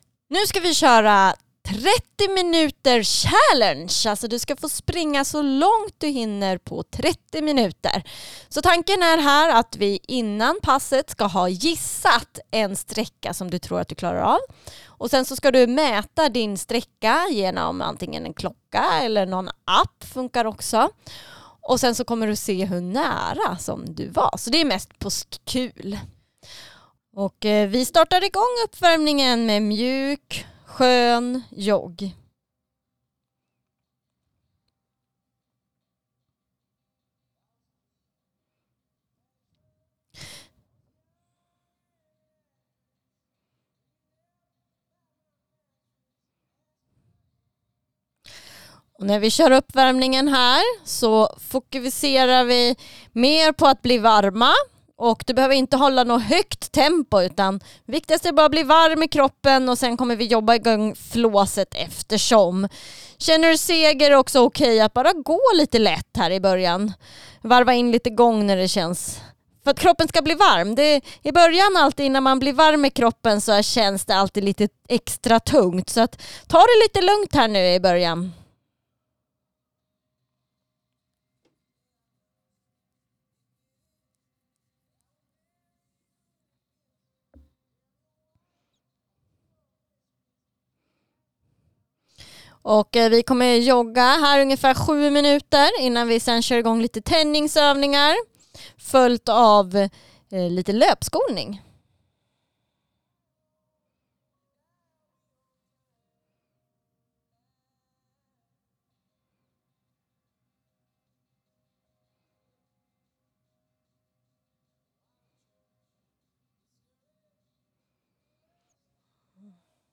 I passen blir du coachad via en ljudfil, där vi leder dig genom passet tillsammans med peppande musik.